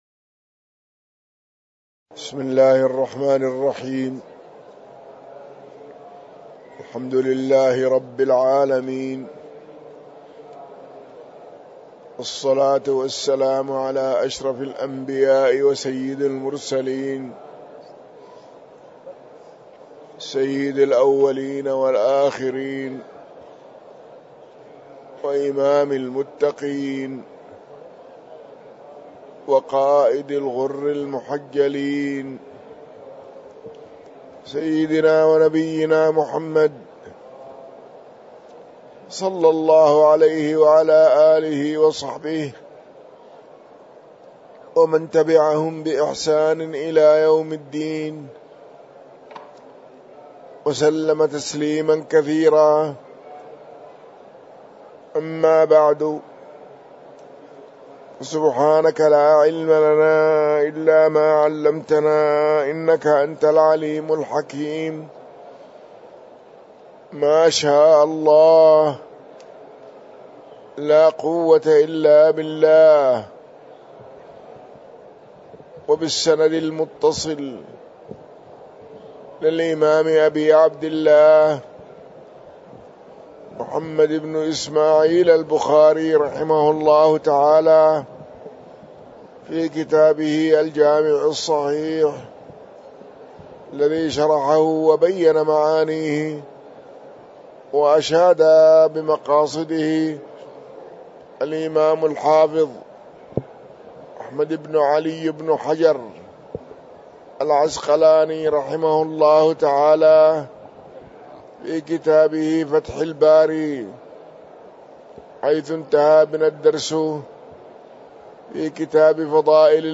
تاريخ النشر ٢ ذو القعدة ١٤٤٤ هـ المكان: المسجد النبوي الشيخ